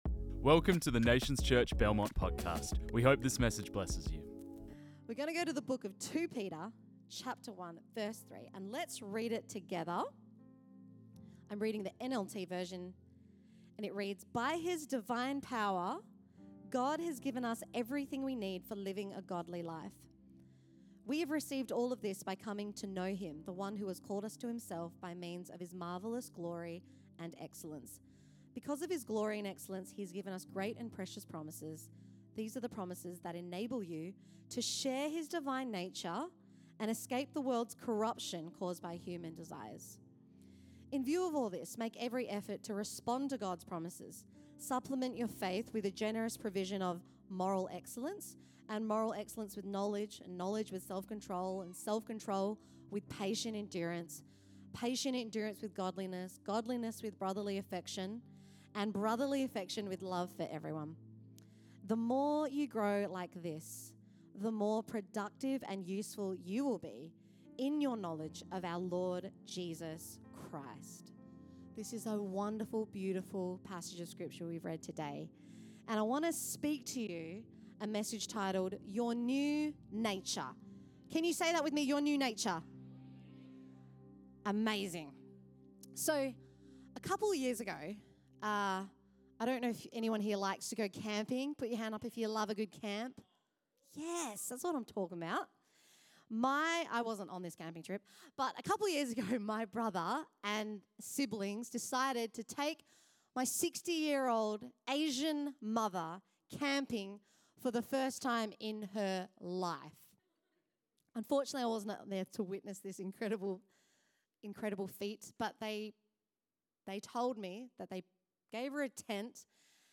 This message was preached on 03 November 2024.